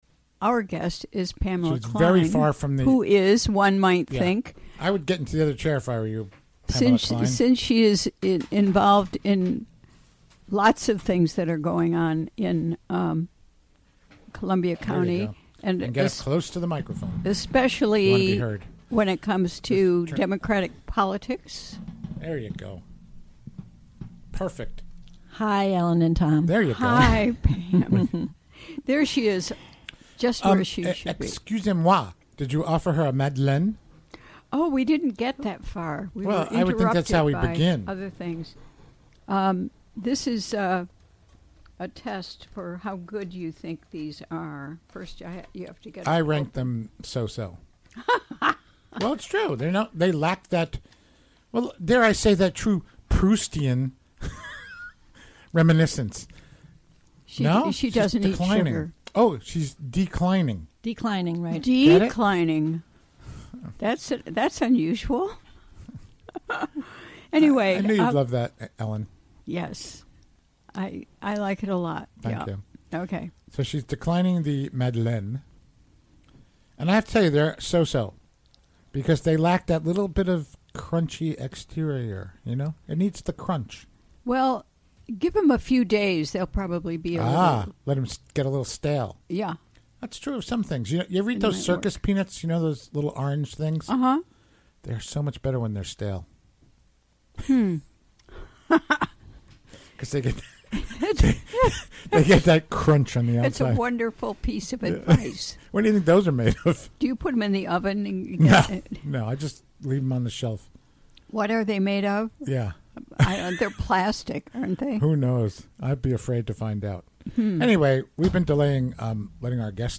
Recorded live during the WGXC Afternoon Show, Thu., Jan. 18.